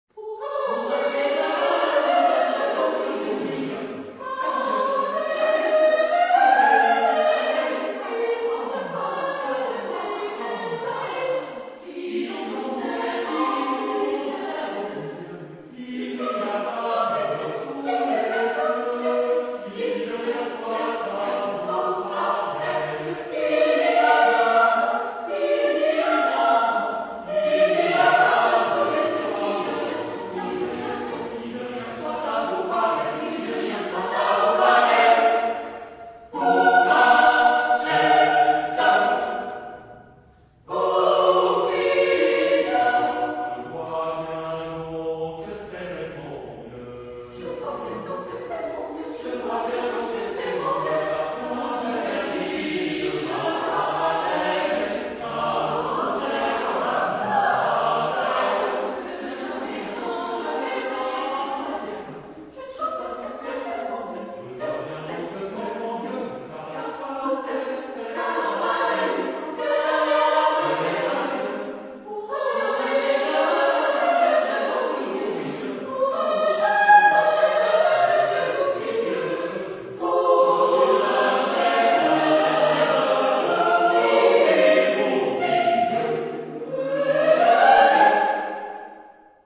Genre-Stil-Form: Liedsatz ; weltlich
Chorgattung: SATB  (4 gemischter Chor Stimmen )
Tonart(en): A-Dur